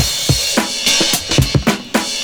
106CYMB03.wav